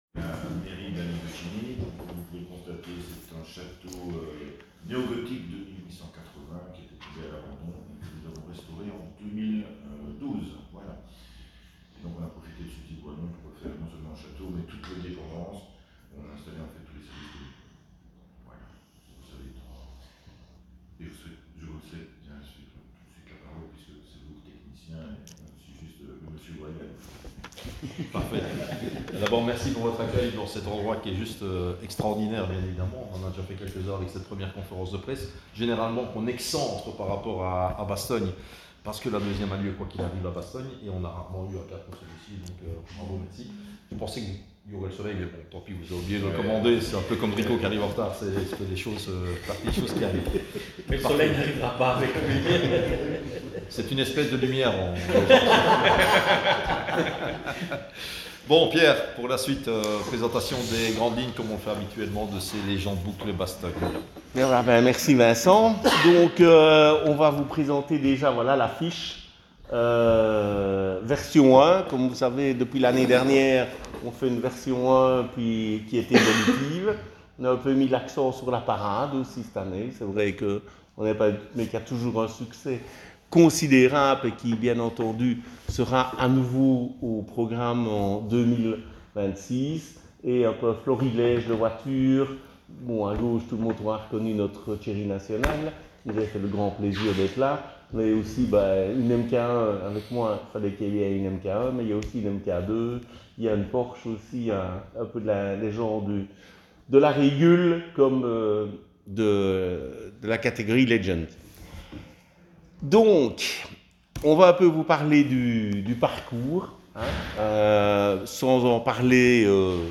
Conférence de Presse 25/10/2025